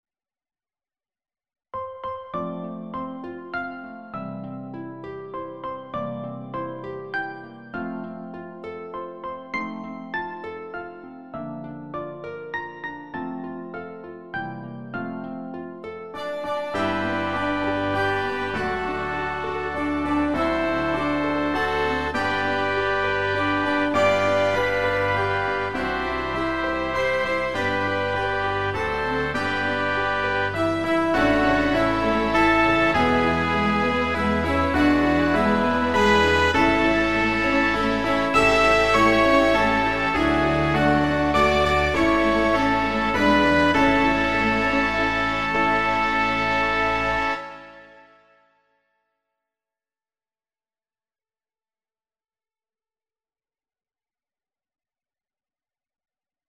Happy Birthday Mp3 And this piece is a well-known song in a different arrangement.